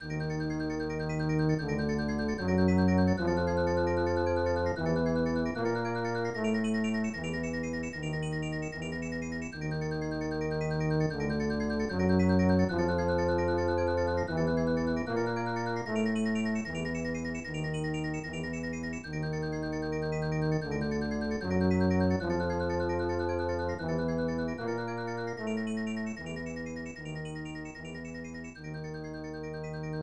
Castle theme
Copyrighted music sample